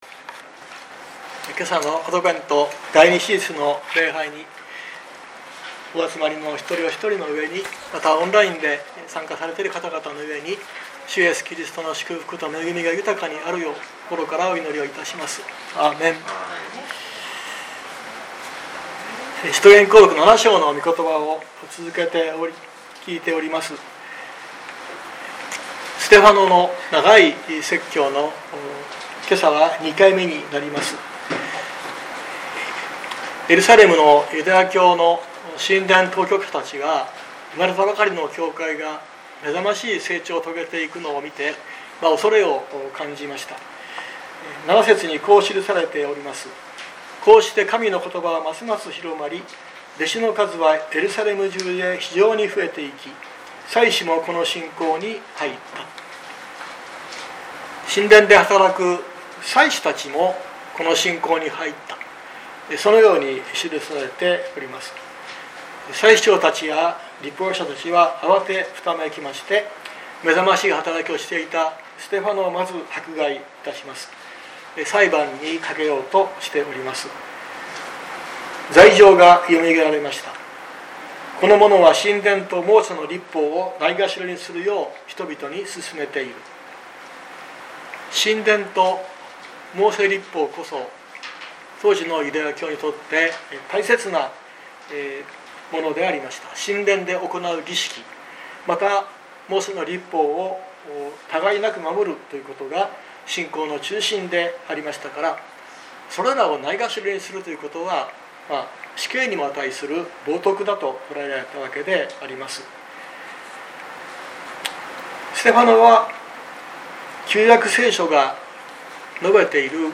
2024年12月08日朝の礼拝「召し出されたモーセ」熊本教会
説教アーカイブ。